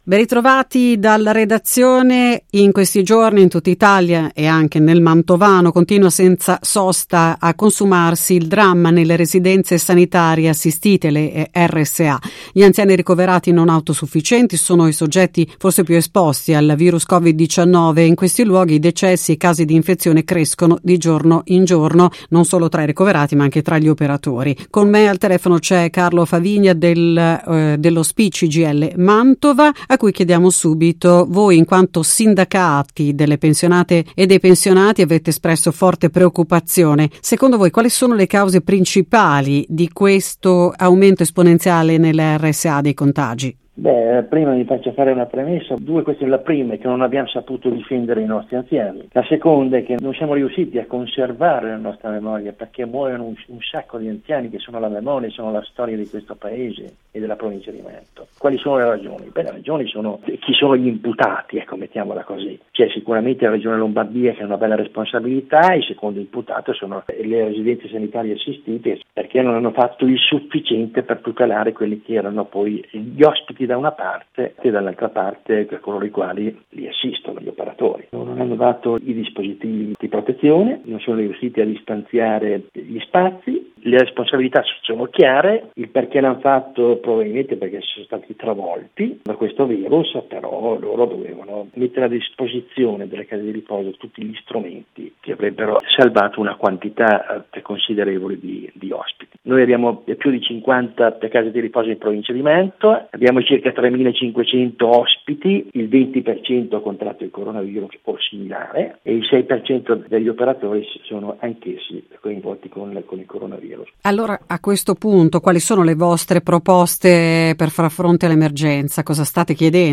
Preoccupati di tale situazione sono i Sindacati delle Pensionate e dei Pensionati, di cui abbiamo intervistato